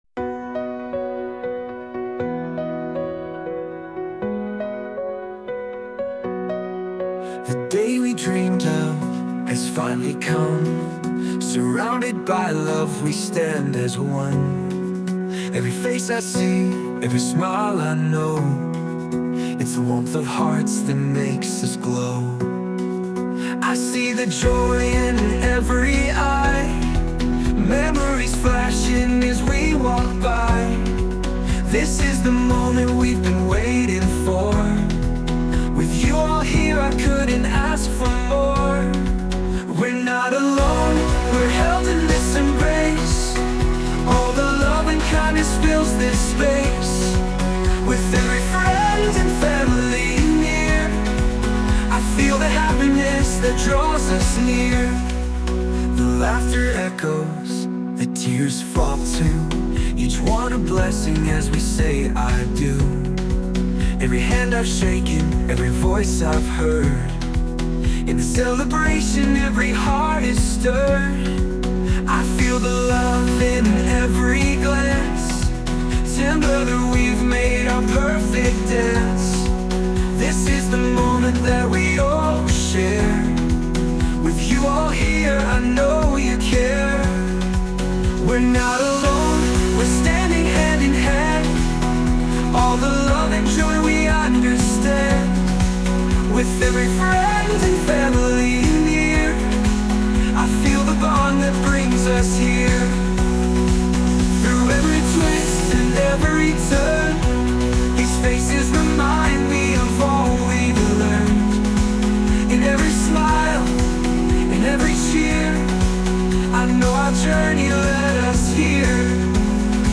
洋楽男性ボーカル著作権フリーBGM ボーカル
男性ボーカル（洋楽・英語）曲です。